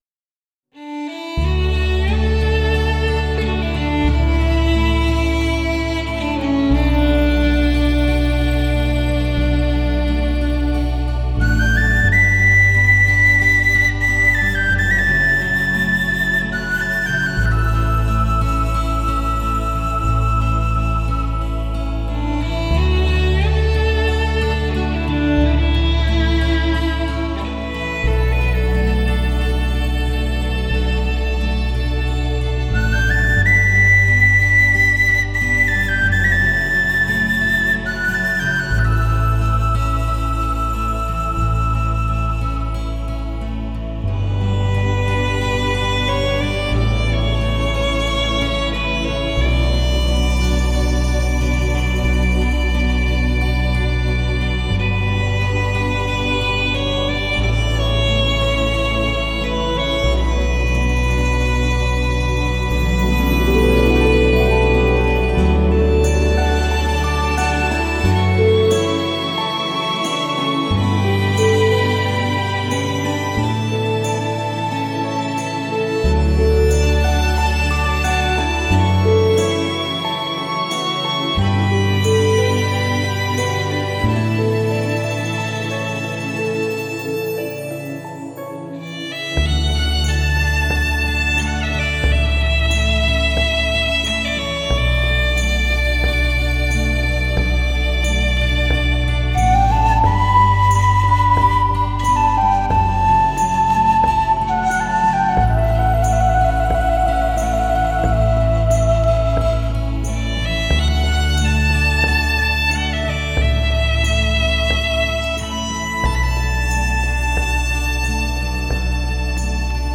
以如梦似幻的清新自然音乐
它的音乐以宽广的音场，逼真的音效，通透的音质而风靡全国